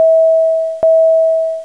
320cabinalert.wav